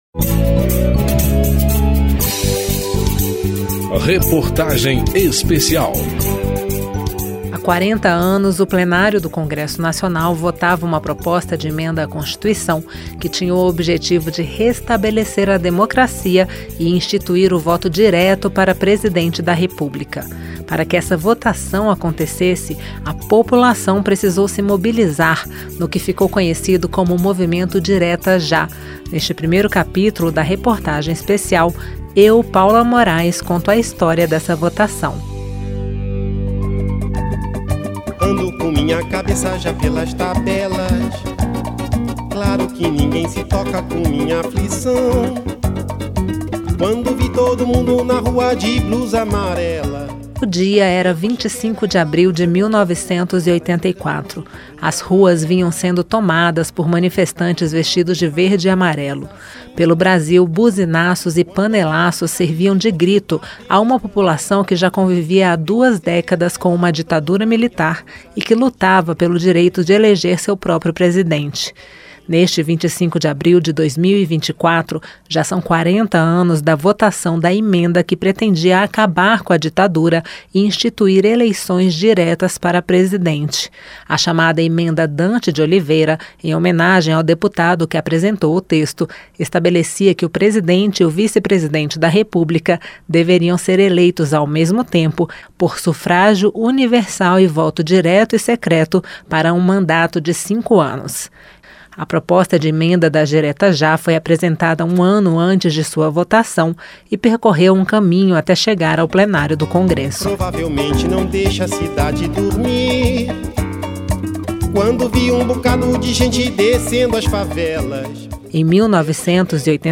Reportagem Especial
Entrevistas nesse capítulo: Airton Soares, ex-deputado federal; Irma Rosseto Passoni, ex-deputada federal; os deputados Alberto Fraga (PL-DF), Laura Carneiro (PSD-RJ) e Coronel Meira (PL-PE).